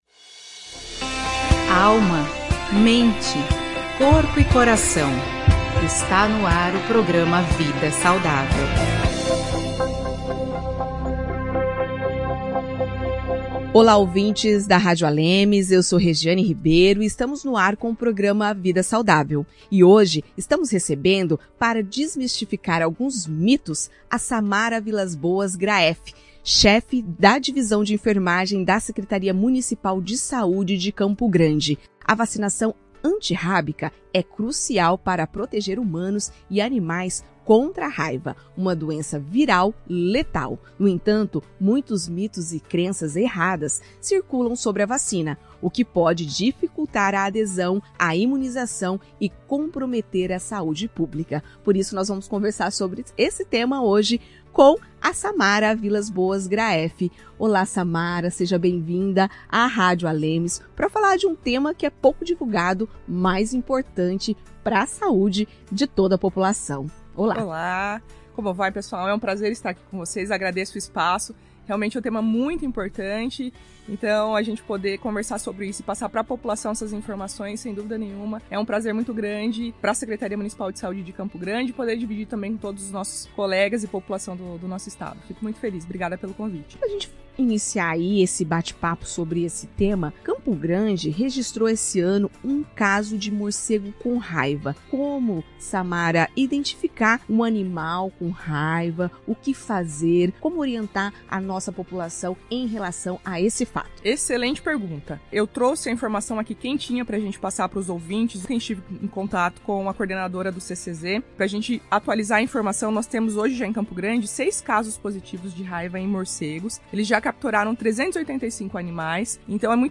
Durante entrevista